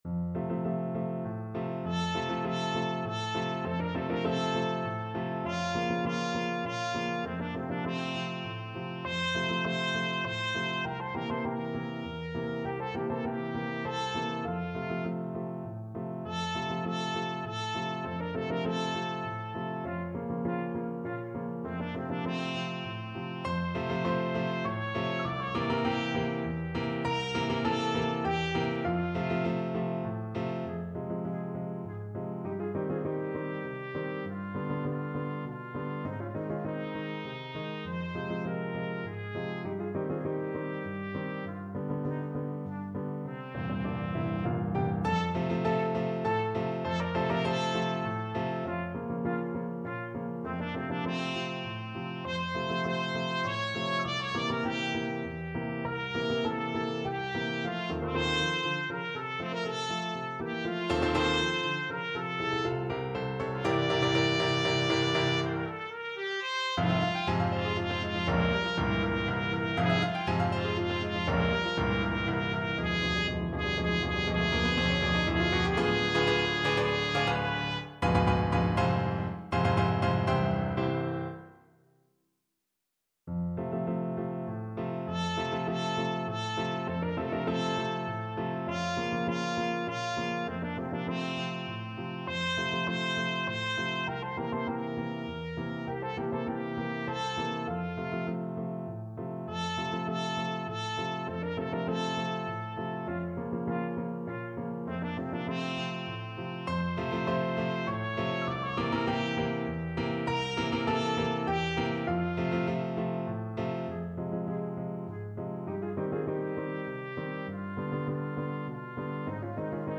Free Sheet music for Trumpet
Trumpet
F major (Sounding Pitch) G major (Trumpet in Bb) (View more F major Music for Trumpet )
3/4 (View more 3/4 Music)
Allegro = 100 (View more music marked Allegro)
Classical (View more Classical Trumpet Music)